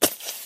mob / creeper / say3.ogg